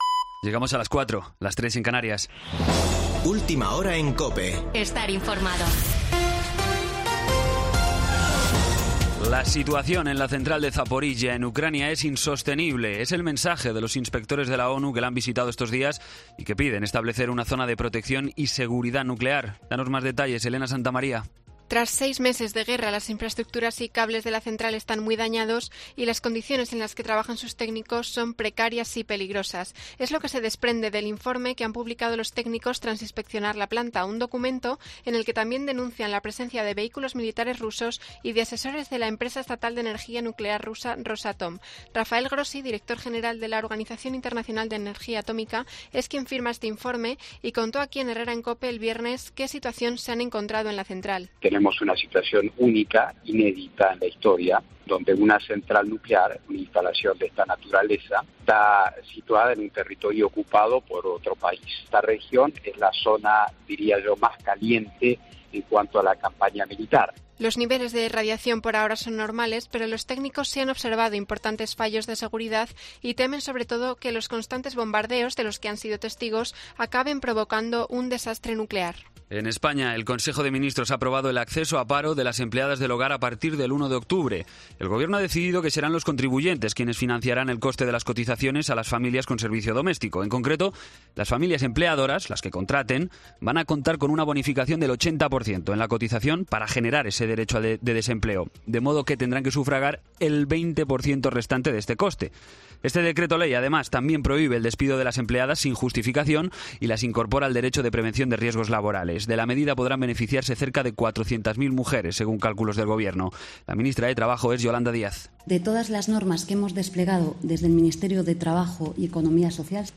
Boletín de noticias de COPE del 7 de septiembre de 2022 a las 04:00 horas
AUDIO: Actualización de noticias Herrera en COPE